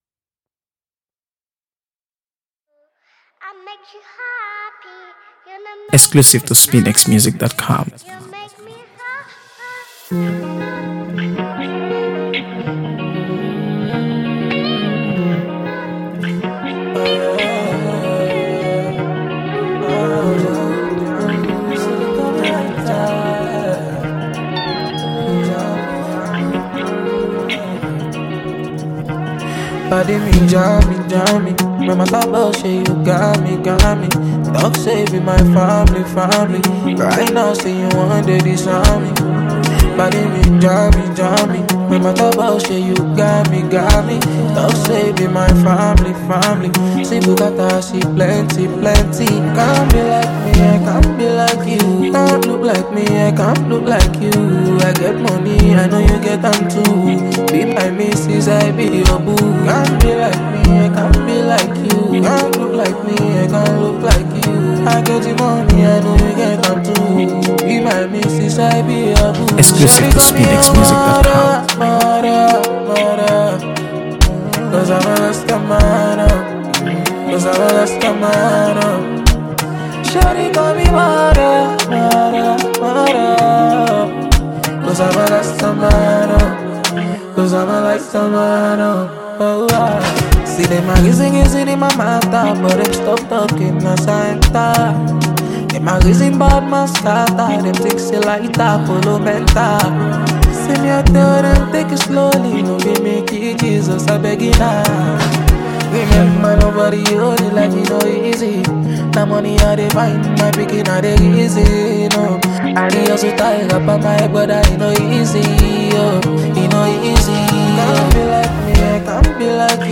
With its infectious rhythm and heartfelt delivery